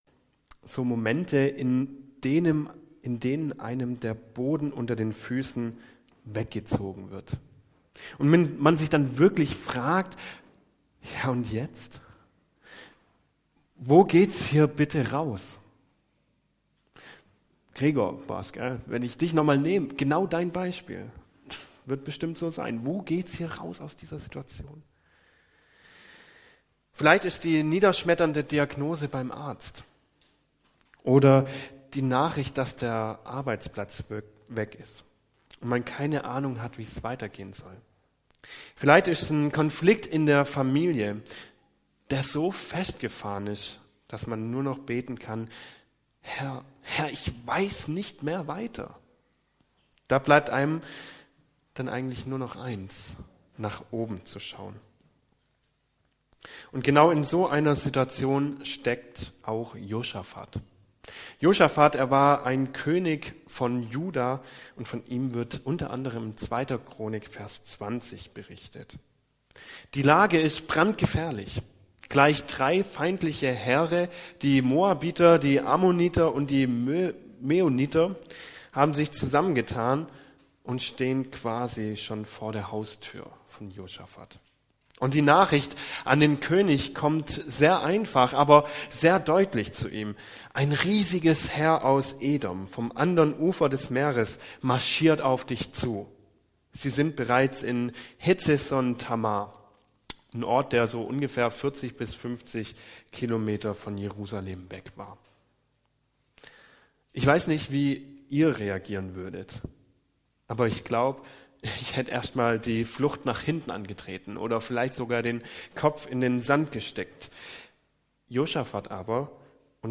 Predigt 11.05.2025 - SV Langenau